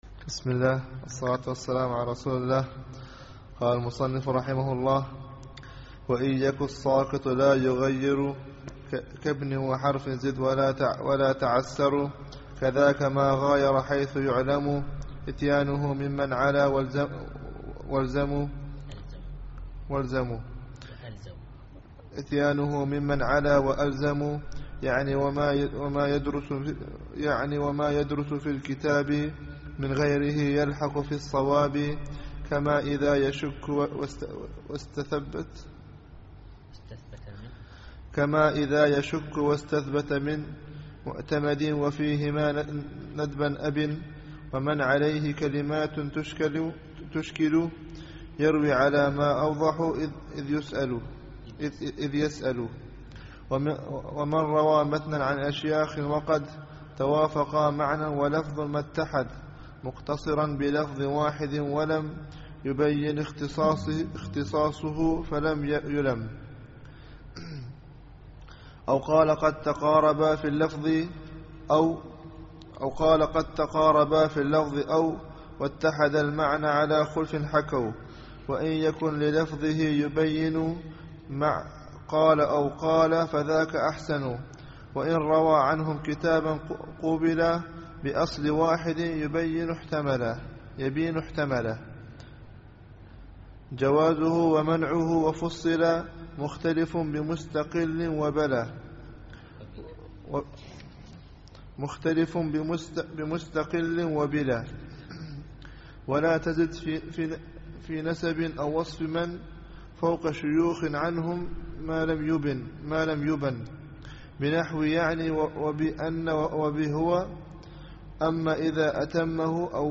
الدرس الثالث والعشرون